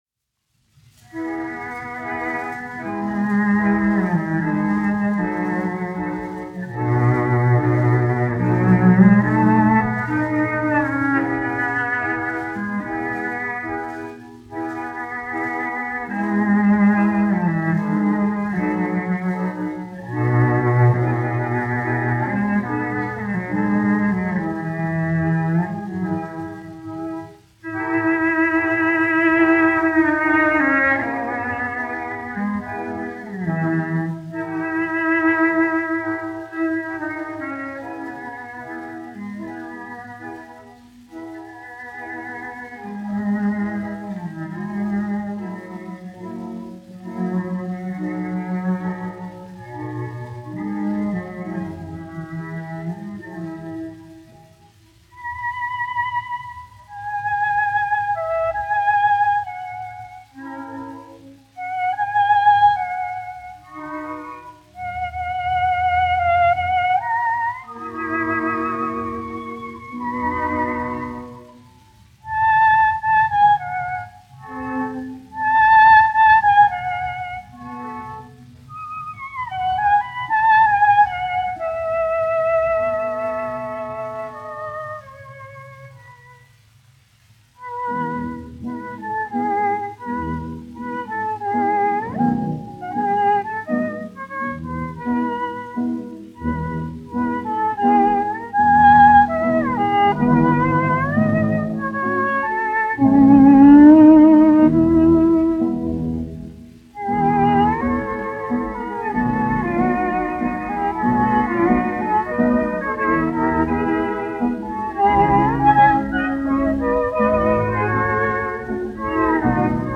1 skpl. : analogs, 78 apgr/min, mono ; 25 cm
Stīgu orķestra mūzika
Skaņuplate